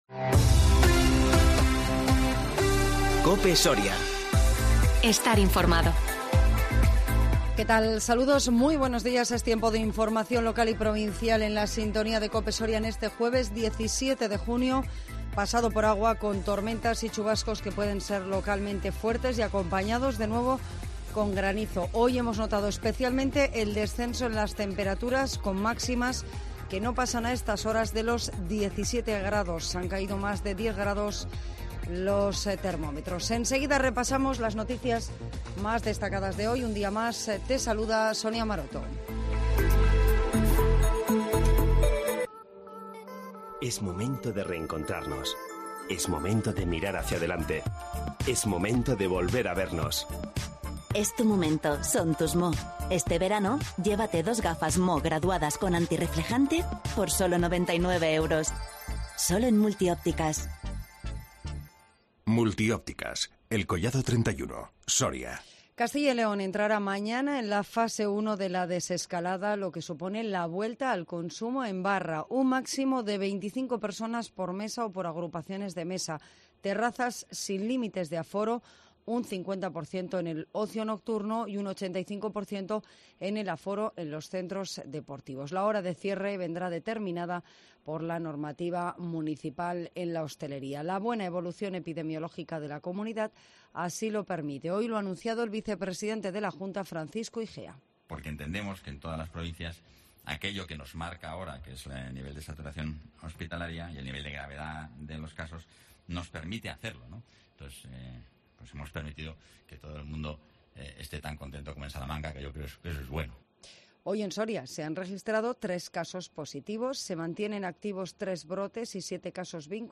INFORMATIVO MEDIODÍA 17 JUNIO 2021